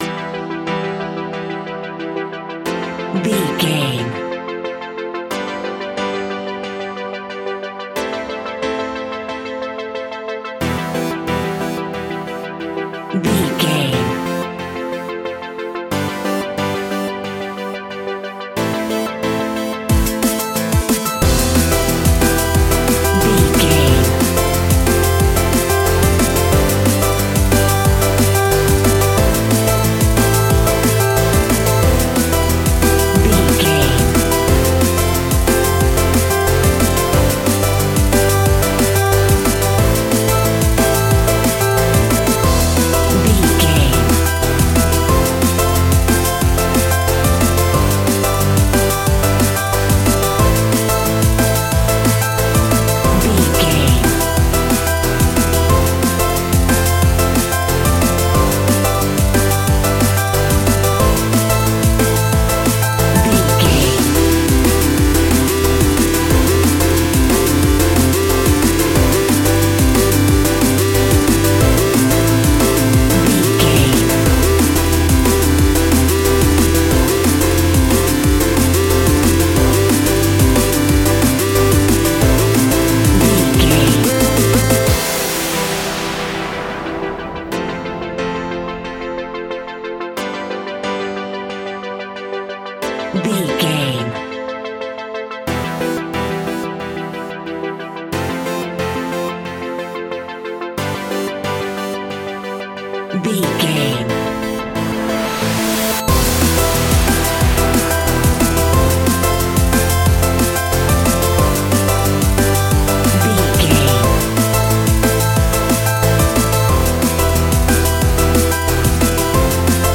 Ionian/Major
Fast
groovy
uplifting
futuristic
driving
energetic
repetitive
drum machine
synthesiser
electronic
sub bass
instrumentals
synth leads
synth bass